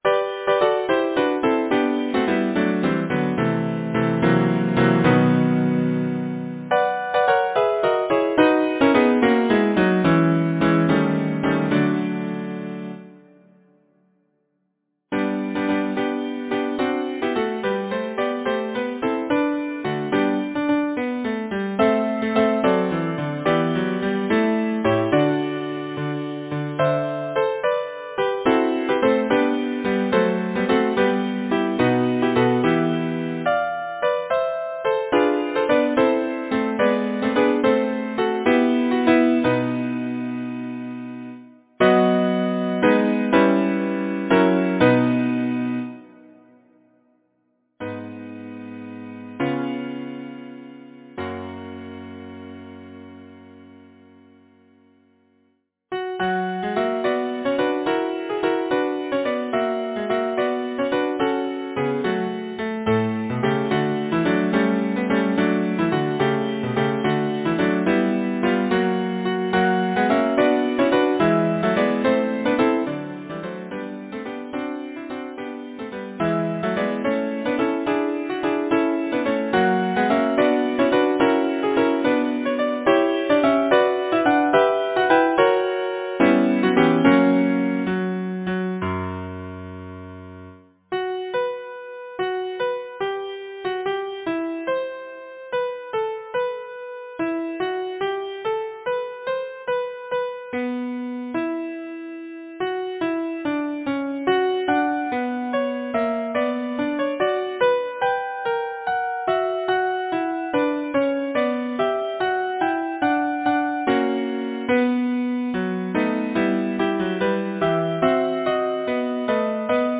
Title: Little Miss Muffet Composer: Arthur E. Fisher Lyricist: Number of voices: 4vv Voicing: SATB Genre: Secular, Partsong, Nursery rhyme
Language: English Instruments: A cappella